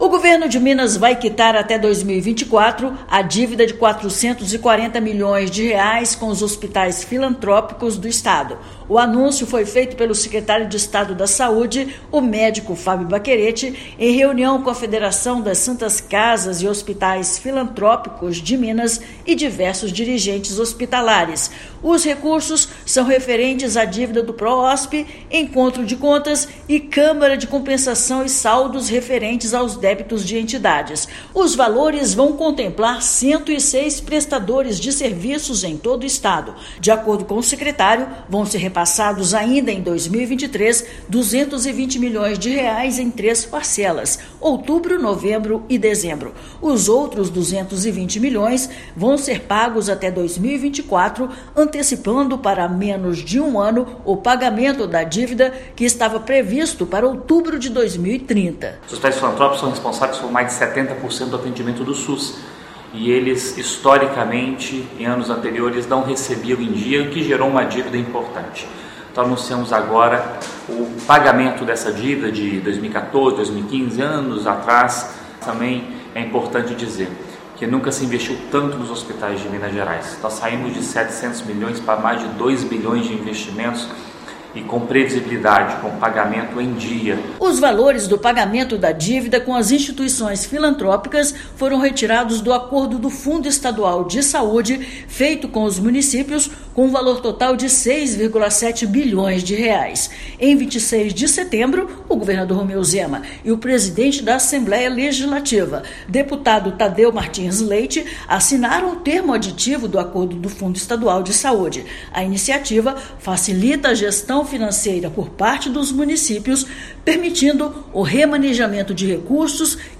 Antecipação do pagamento promove melhoria do fluxo de caixa às instituições e garante atendimento à população. Ouça matéria de rádio.